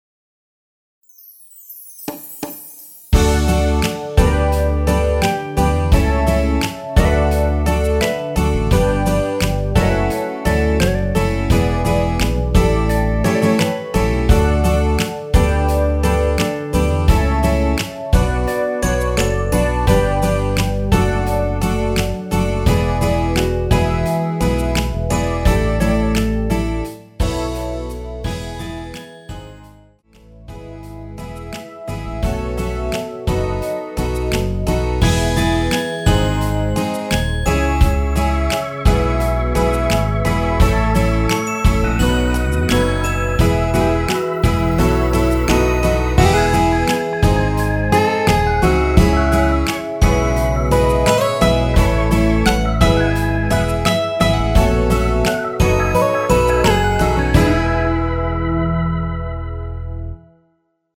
대부분의 여성분이 부르실수 있는키로 제작 하였습니다.
엔딩이 페이드 아웃이라 라이브 하시기 좋게 엔딩을 만들어 놓았습니다.(미리듣기 참조)
앞부분30초, 뒷부분30초씩 편집해서 올려 드리고 있습니다.